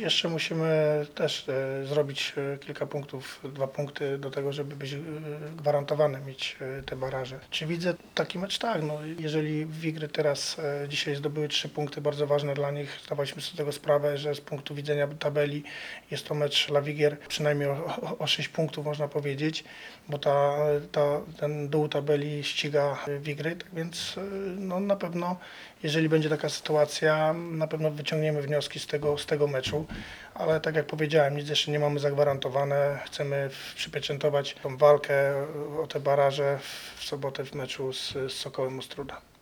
27 IV 2022;; Suwałki – Stadion Miejski; eWinner 2 liga; Wigry – Motor Lublin 3:2; Marek Saganowski